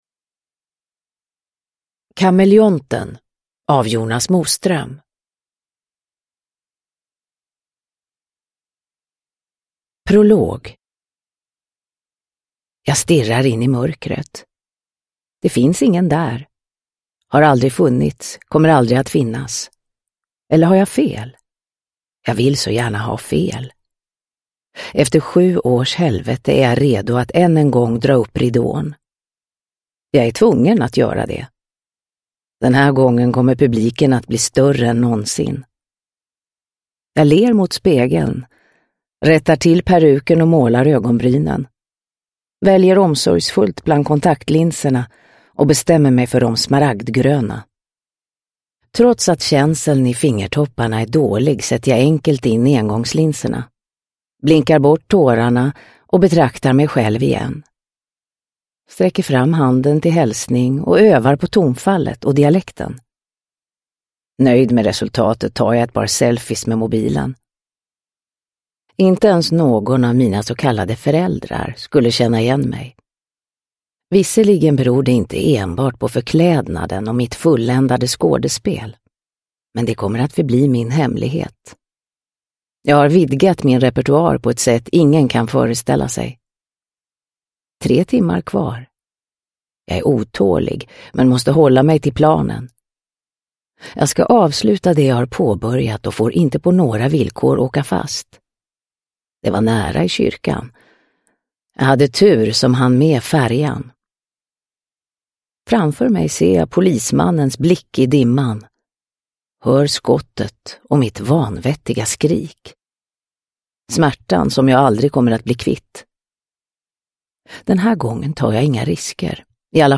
Kameleonten – Ljudbok – Laddas ner
Uppläsare: Marie Richardson